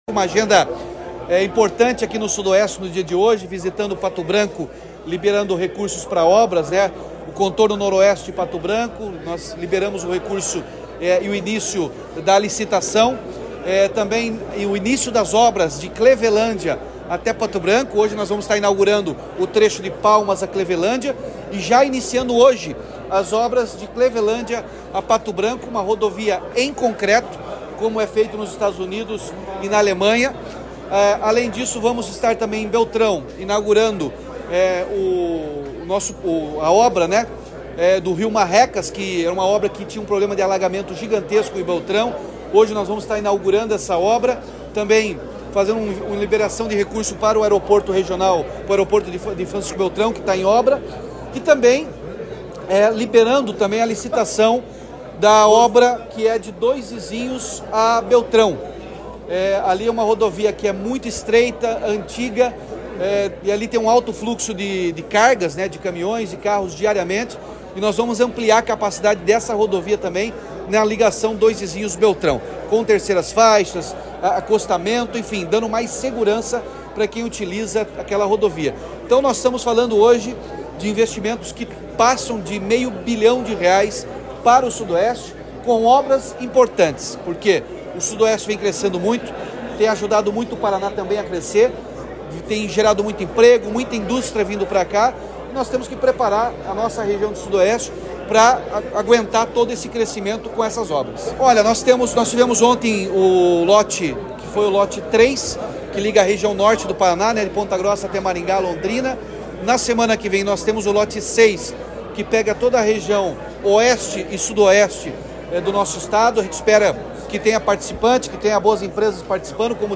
Sonora do governador Ratinho Junior sobre o anúncio de novas obras na região Sudoeste